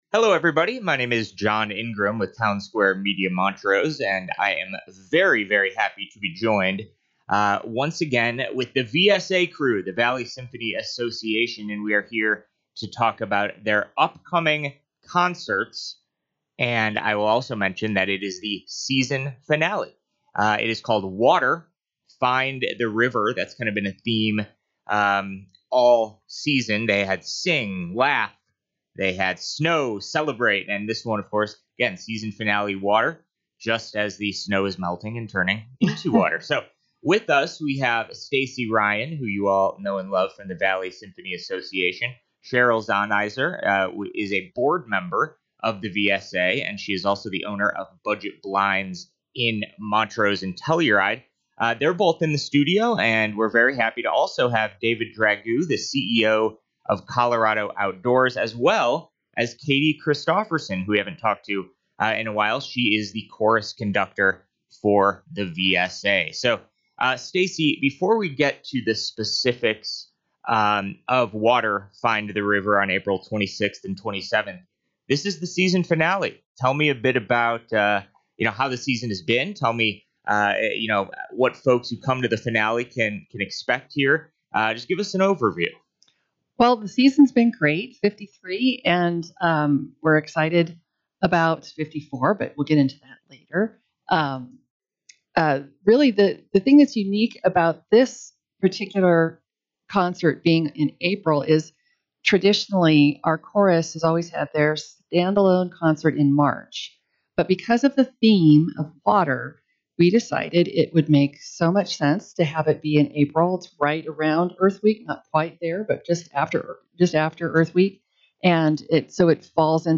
Radio Interview with Our Season Co-Partner
Radio-interview-Water-concert.mp3